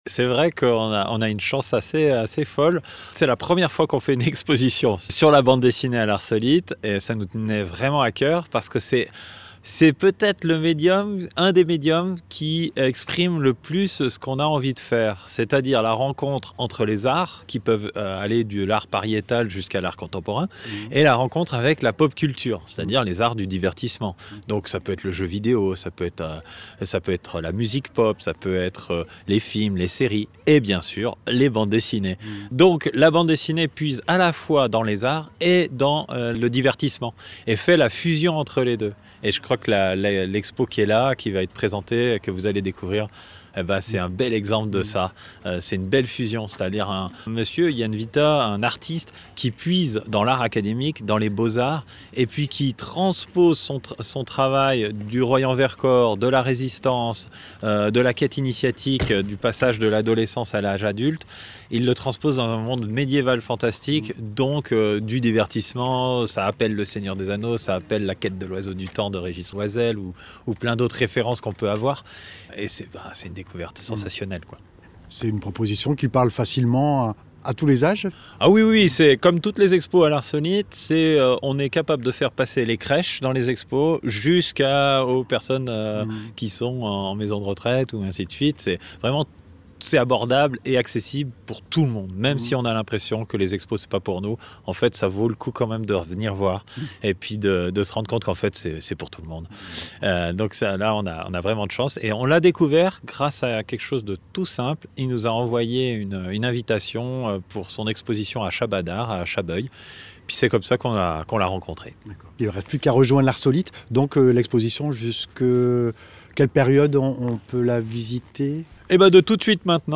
L’exposition immersive invite le public à découvrir les coulisses d’un univers de fantasy profondément enraciné dans la réalité du Vercors : résistance, quête initiatique et nature. » Entretien